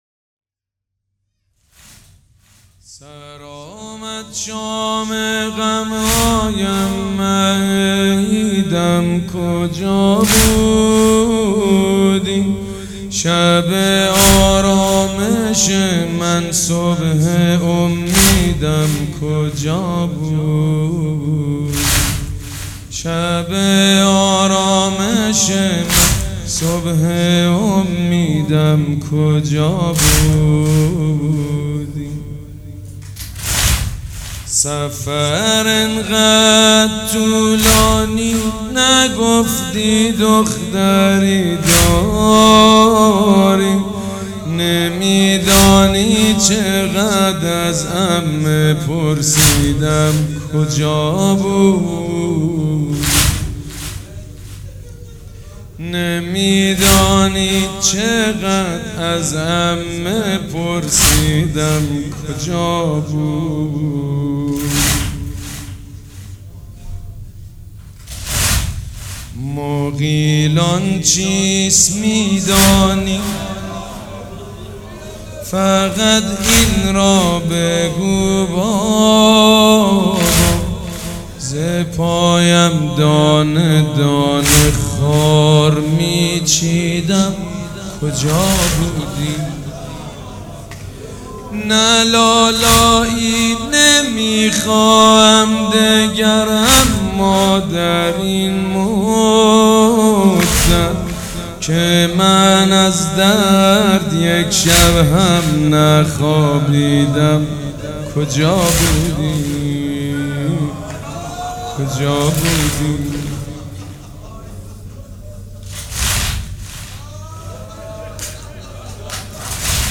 سبک اثــر واحد
مداح حاج سید مجید بنی فاطمه
مراسم عزاداری شب سوم